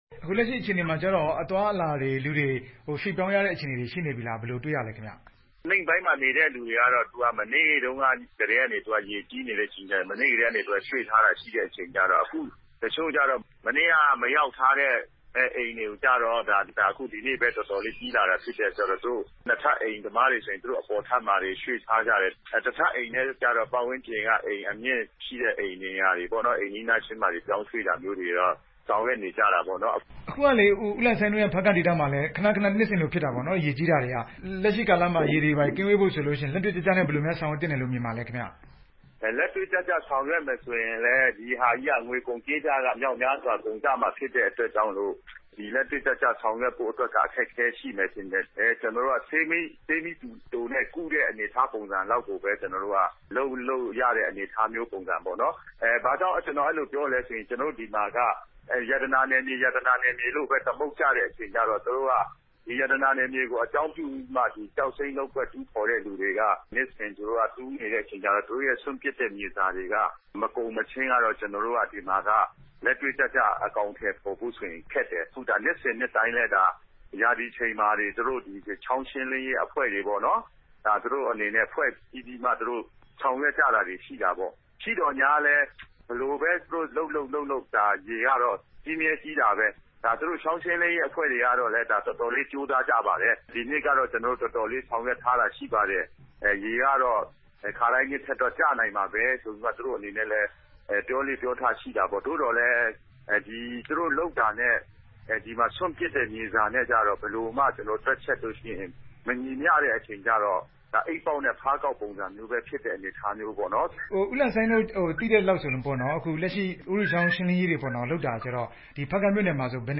ဖားကန့်ဒေသ ရေကြီးတဲ့အကြောင်း မေးမြန်းချက်